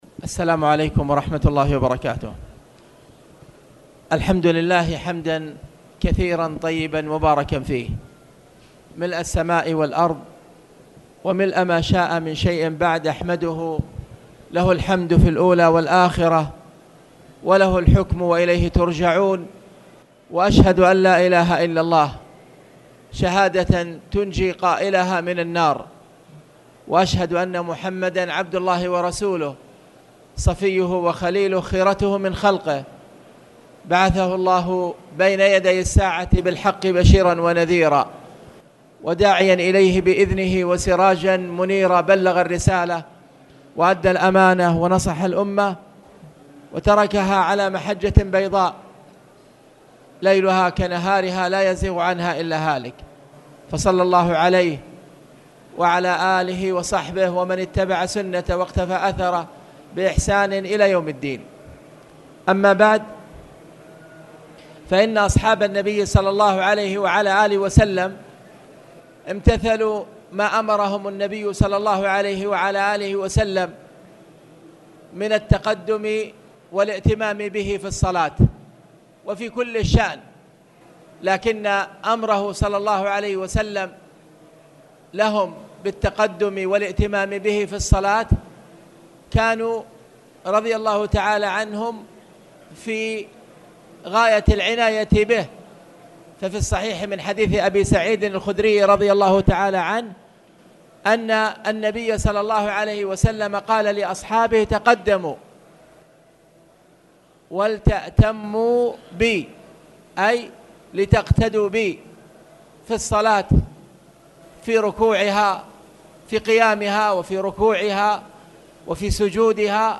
تاريخ النشر ٢ شعبان ١٤٣٨ هـ المكان: المسجد الحرام الشيخ